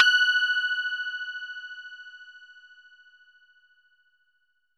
SPOOKY F#5.wav